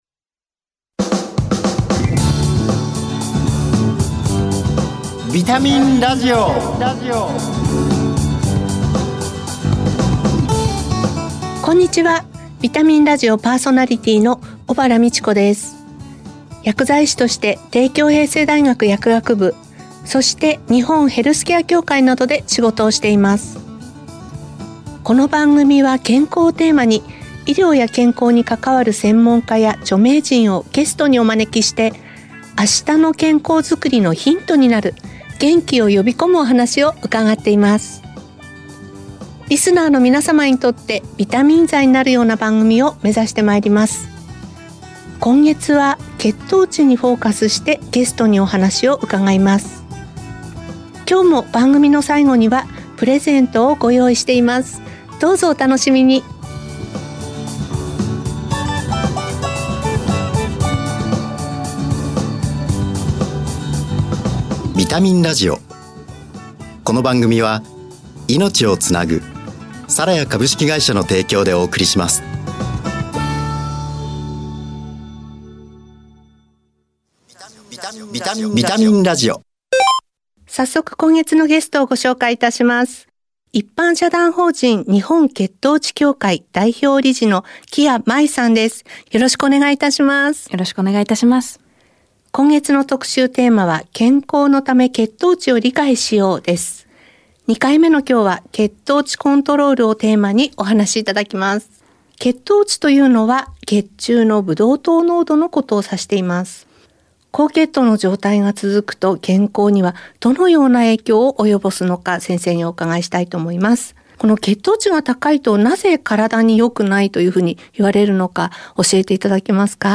「健康」をテーマに、元気になる情報をお届けします。医療従事者など専門家がゲストに登場。